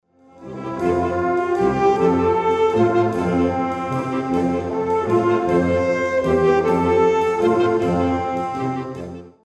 Audiobeispiel eines Saxophonsatzes
Audiobeispiel Saxophonsatz
saxophonsatz.mp3